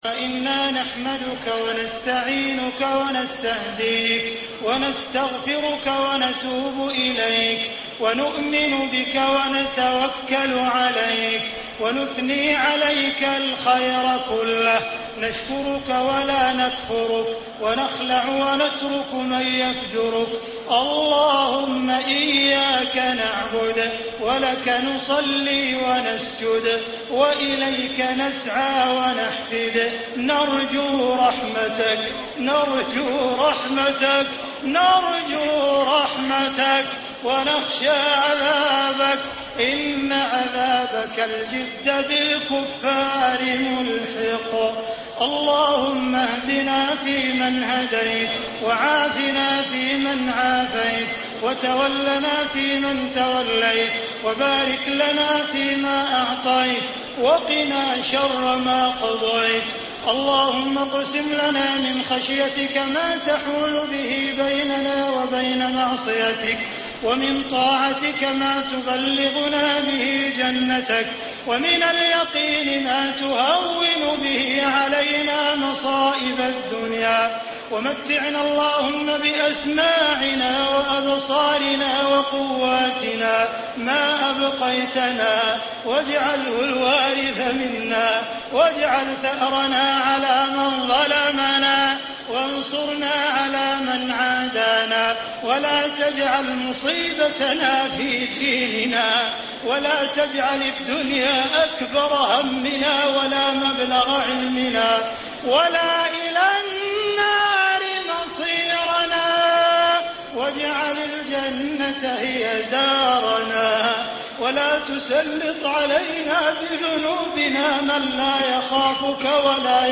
أروع دعاء للشيخ احمد العجمي الممنوع من العرض